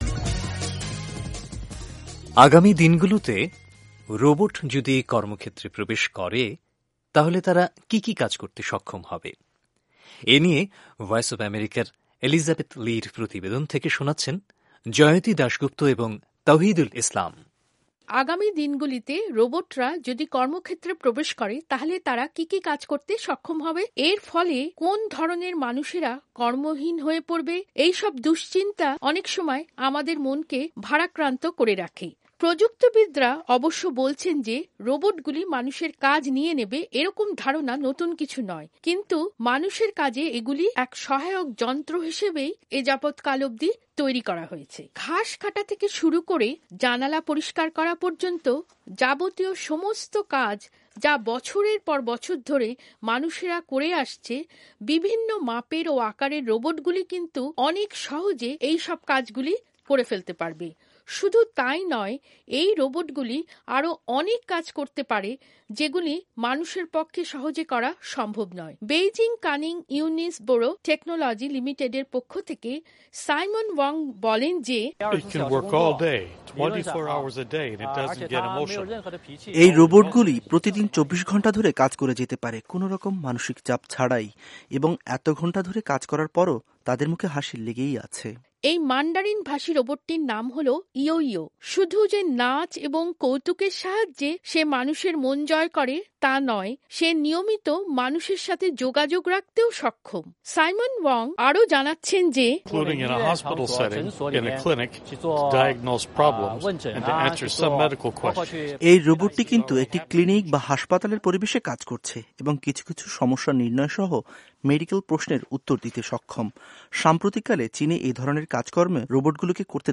রোবট সম্পর্কিত প্রতিবেদনটি পড়ে শোনাচ্ছেন